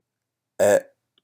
japanese_e_vowel.m4a